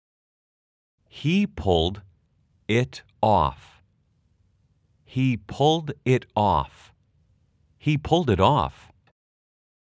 [겁없이 잉글리시 20일 동사편]의 문장은 단어부터 또박또박 연습하고, 조금 빠르게, 아주 빠르게 3가지 속도로 구성되어 있습니다.
/ 히 푸울딧 오오프/
pulled it /푸울드 잇/은 붙여서 /푸울딧/으로 발음하세요.